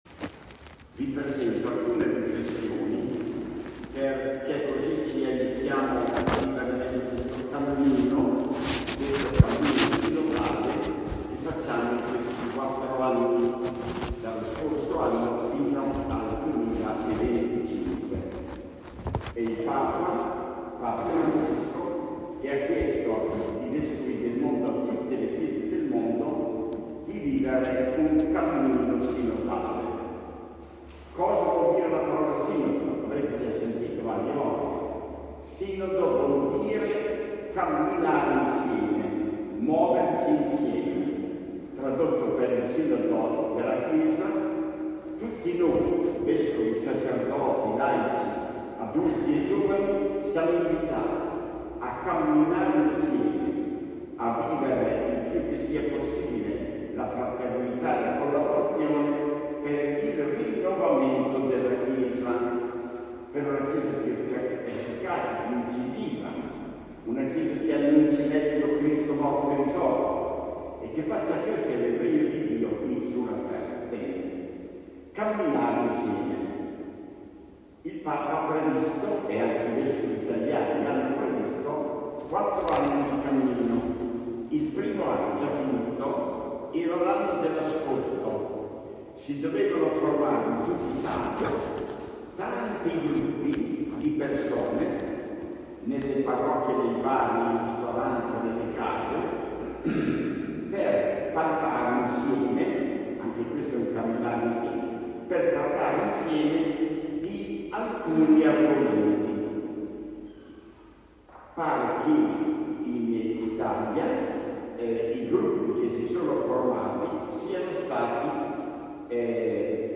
Registrazione audio dell'omelia di mons. Verucchi.
Giovedì 13 ottobre si è tenuta nella chiesa parrocchiale di Ciano l'ultima celebrazione del 13 del mese.